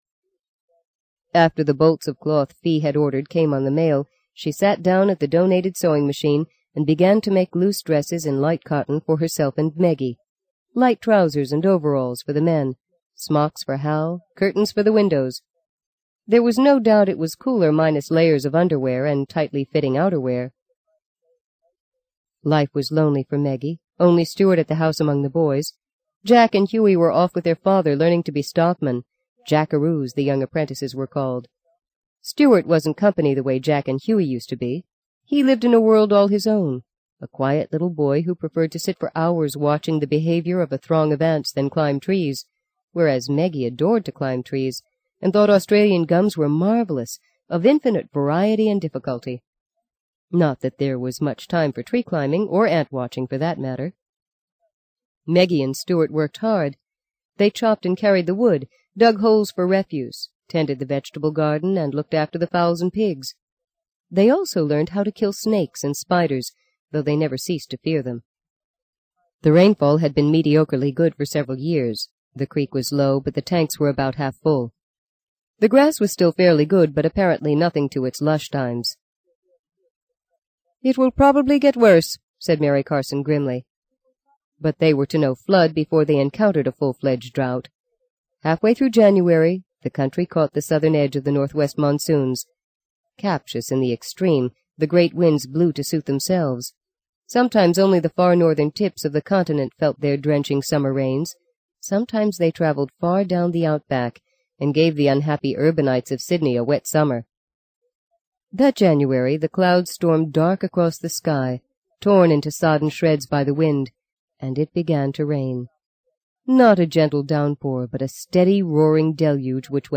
在线英语听力室【荆棘鸟】第四章 08的听力文件下载,荆棘鸟—双语有声读物—听力教程—英语听力—在线英语听力室